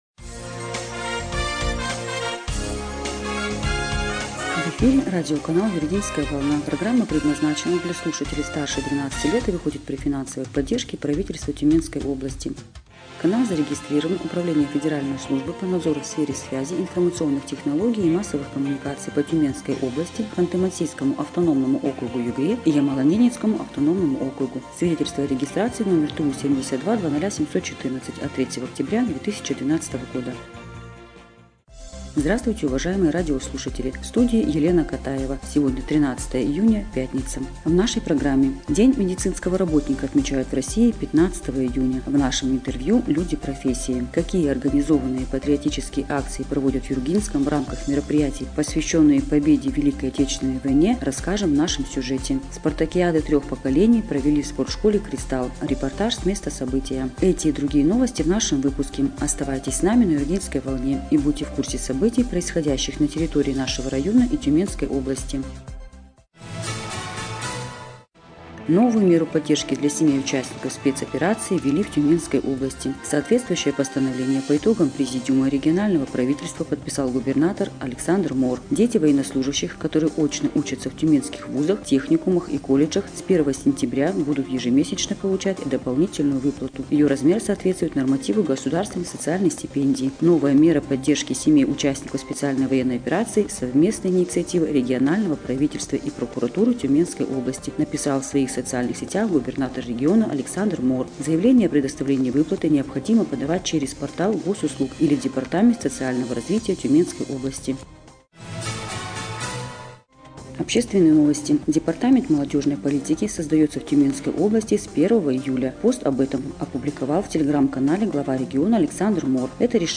Эфир радиопрограммы "Юргинская волна" от 13 июня 2025 года